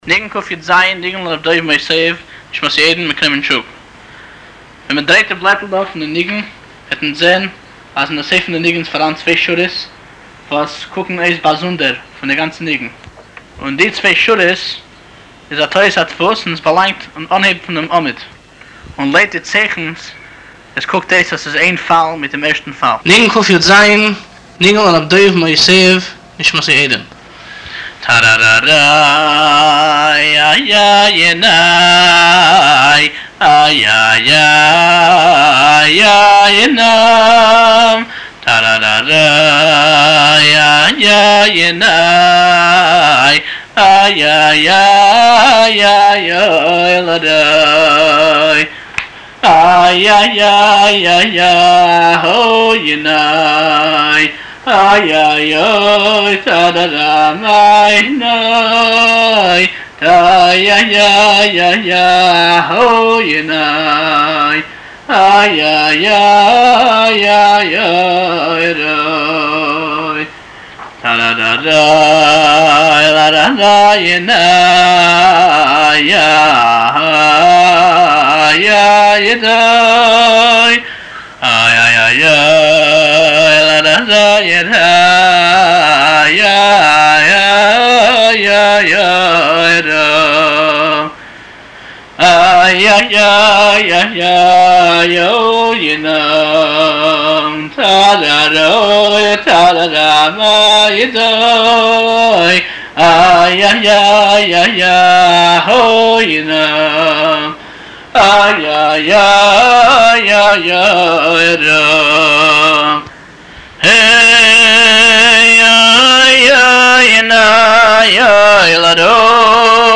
הניגון לר' דוב מוסיוב (בכתיב אידי: מאסיעוו) הינו ניגון חב"די פנימי החודר לכל נימי הנשמה והלב. הניגון תובע ודורש ואינו מניח להשקיטו ולהתנצל, עד שבבא האחרונה אתה שומע צליל של החלט גמור לעמוד בתוקף מבלי חת נגד כל מונע ומעכב לעבודת השם.